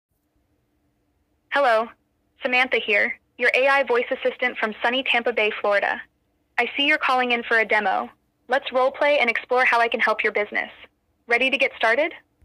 AI Voice Assistant Voices | Natural Phone Voice Demos
Original Voice Library (Legacy)
These are our earlier generation voices. They’re still clear and reliable — but if you compare them with the 2025 voices above, you’ll hear how much more natural and expressive the new models are.